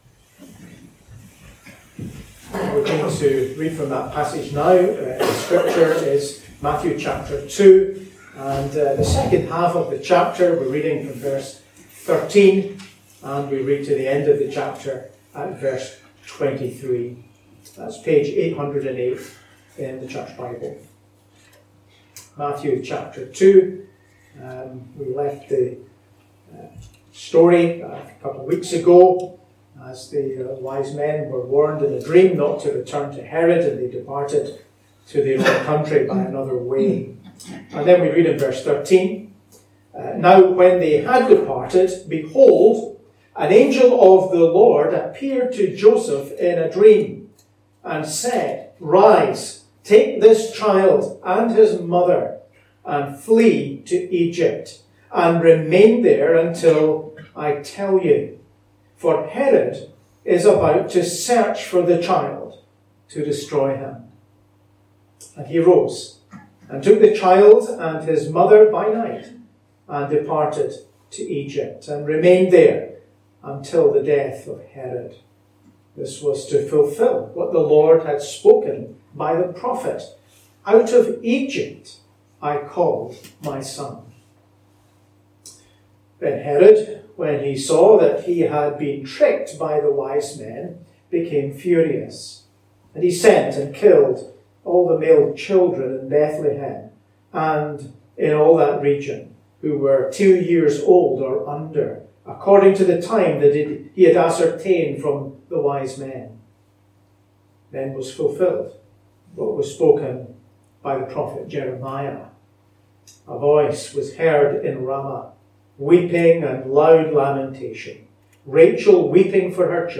A link to the video recording of the 6:00pm service, and an audio recording of the sermon.